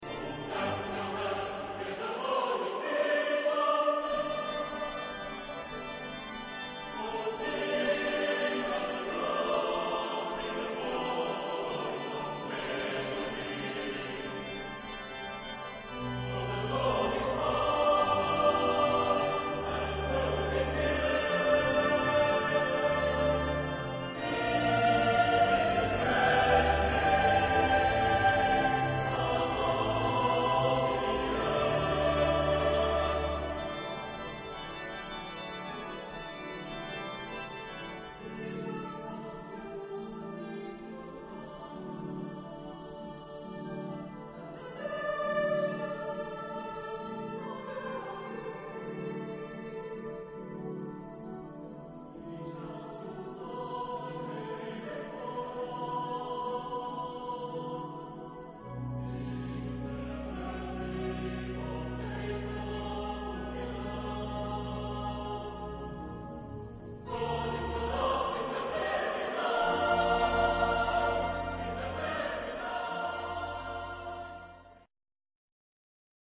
Tipo del material: Coro y organo
Género/Estilo/Forma: Sagrado ; Salmo ; Himno (sagrado)
Carácter de la pieza : luminoso ; rítmico
Tipo de formación coral: SATB  (4 voces Coro mixto )
Instrumentos: Organo (1)
Tonalidad : sol mayor